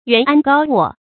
yuán ān gāo wò
袁安高卧发音
成语注音 ㄧㄨㄢˊ ㄢ ㄍㄠ ㄨㄛˋ